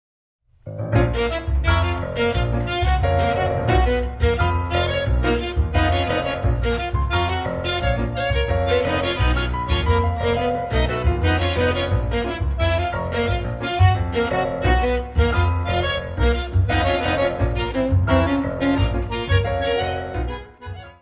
piano, congas
violino
contrabaixo
Bandoneón
: stereo; 12 cm
Music Category/Genre:  World and Traditional Music